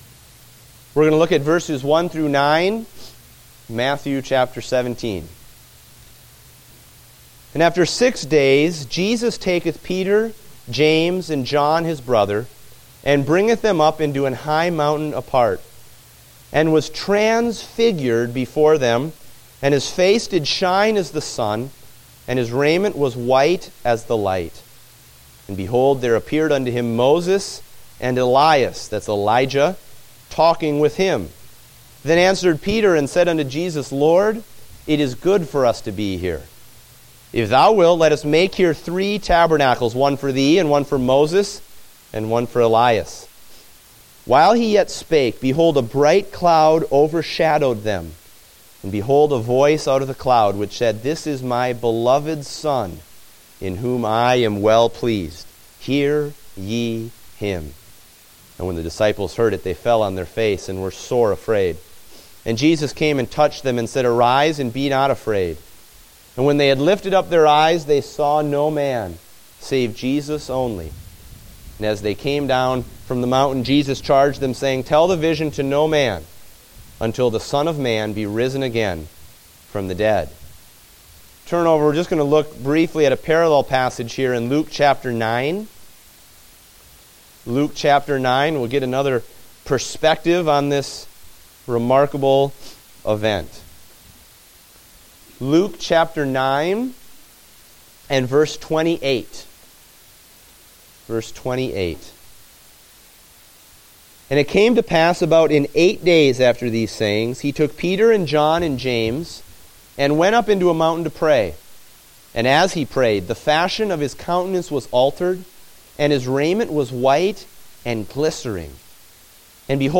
Date: December 27, 2015 (Adult Sunday School)